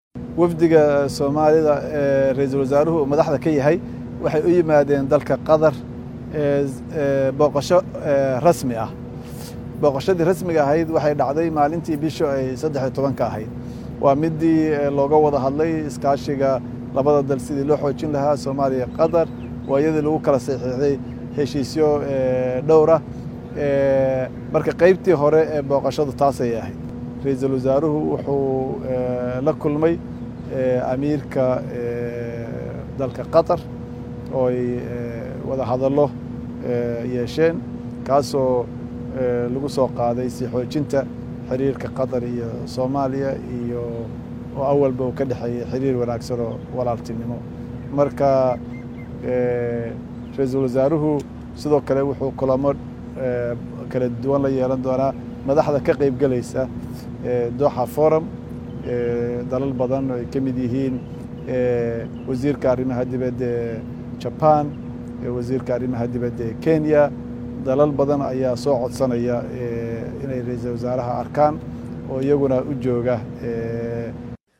Dhageyso Codka Wasiirka Arrimaha Dibadda Soomaaliya Axmed Ciise Cawad oo kulankaas ka warbixinaya.